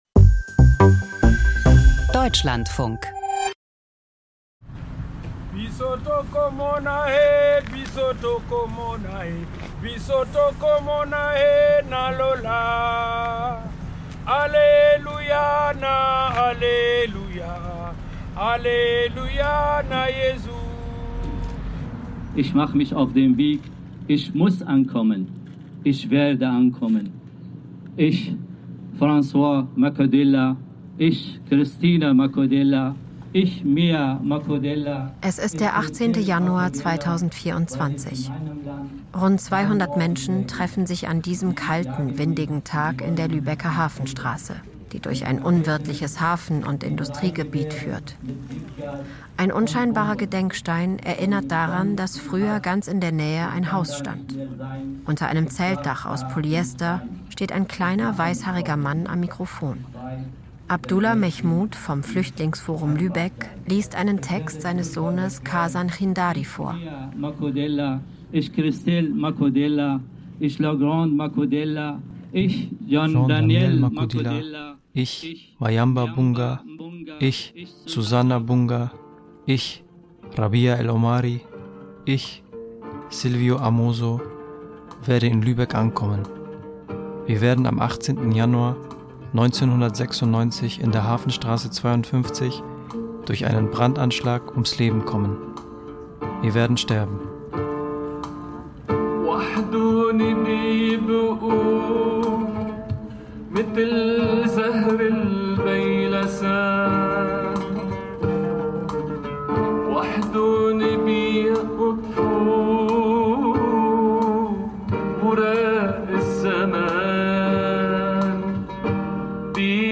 Feature Lübeck und der tödlichste Brandanschlag der 1990er-Jahre Unsere Asche wird weiter brennen 54:36 Minuten Die Bilder des Brandanschlags auf eine Unterkunft für Geflüchtete in Lübeck am 18.